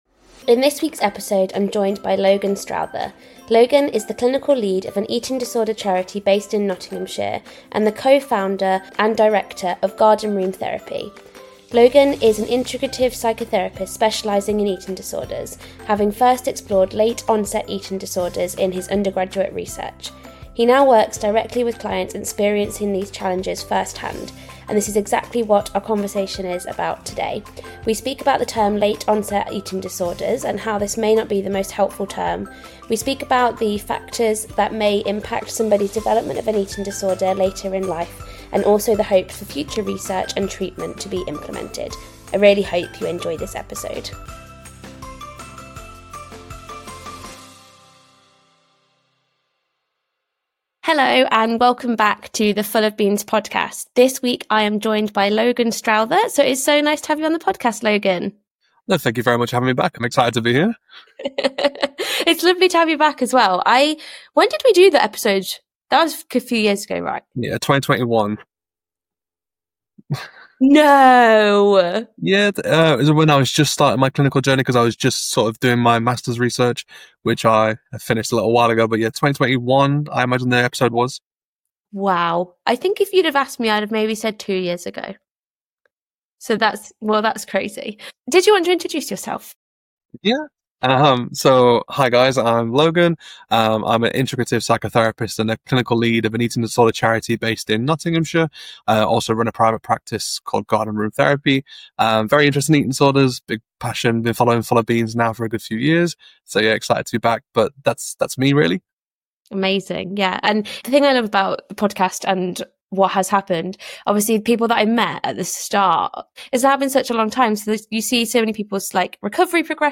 You are getting a sneak peek into a conversation with one of my oldest friends
I even forget we were on a podcast at one point because this is just who we are and how we talk.